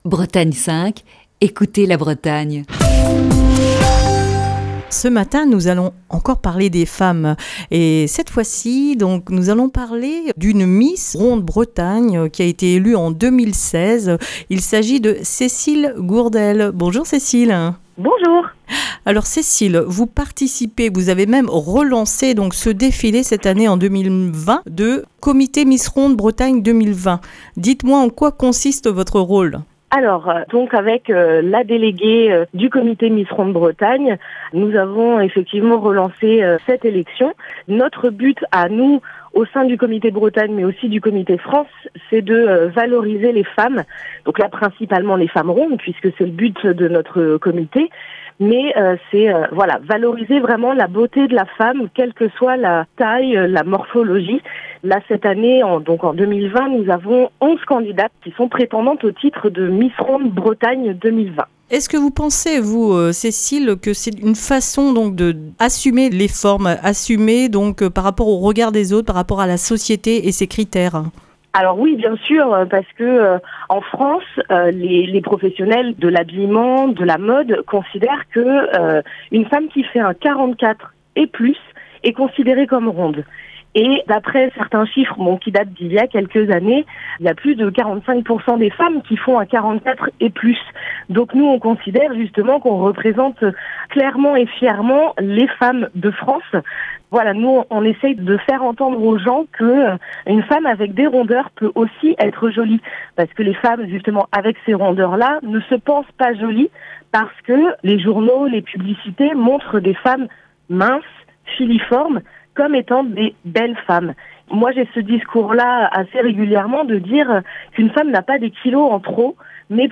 Ce jeudi dans le coup de fil du matin
est au téléphone